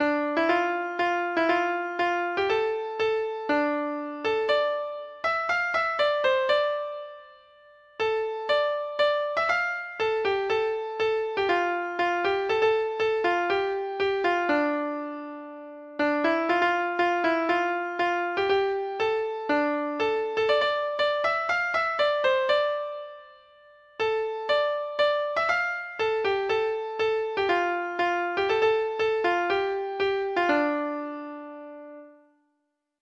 Folk Songs from 'Digital Tradition' Letter T The False Young Man
Treble Clef Instrument  (View more Intermediate Treble Clef Instrument Music)
Traditional (View more Traditional Treble Clef Instrument Music)